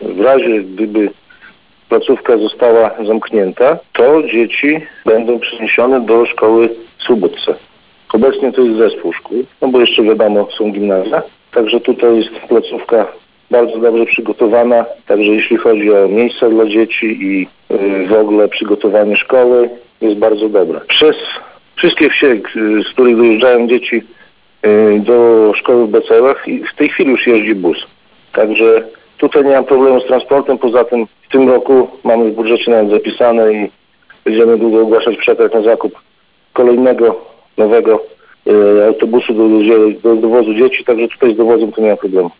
Jak mówi Mariusz Grygieńć, wójt gminy Szypliszki, aktualnie do jednostki uczęszcza 30 uczniów.
Mariusz-Grygieńć-wójt-gminy-Szypliszki-02.mp3